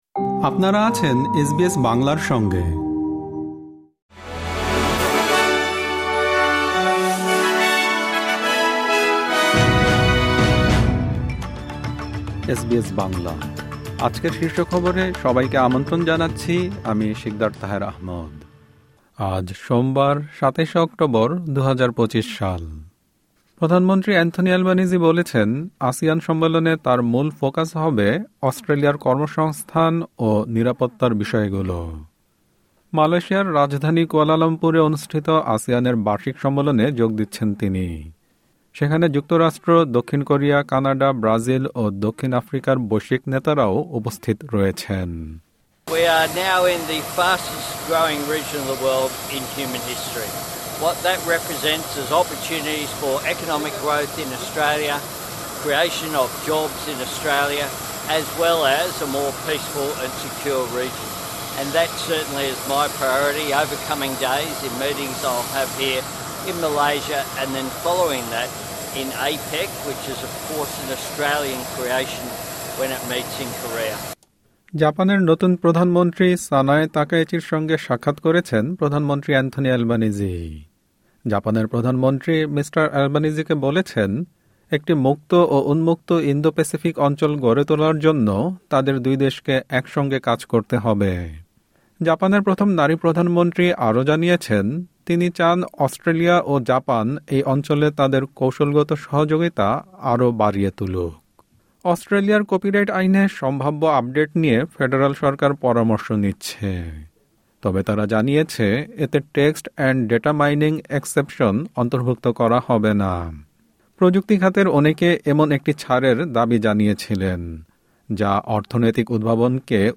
এসবিএস বাংলা শীর্ষ খবর: ২৭ অক্টোবর, ২০২৫